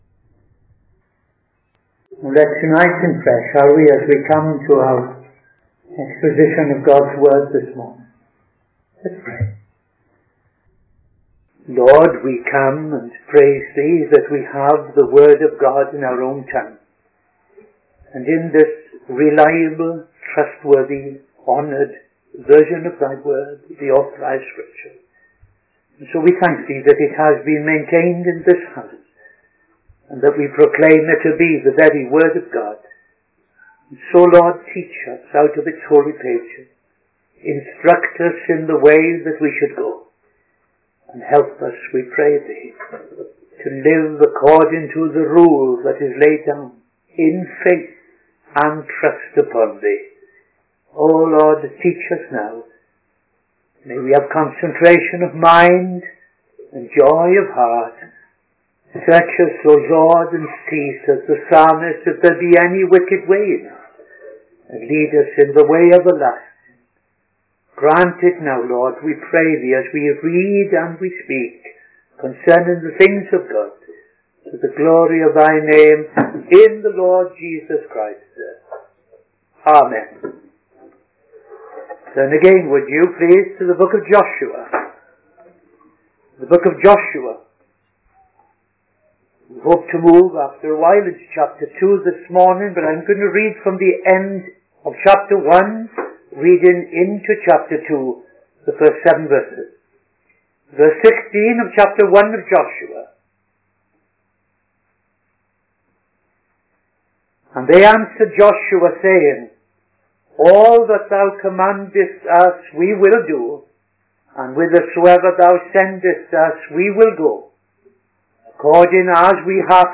Midday Sermon - TFCChurch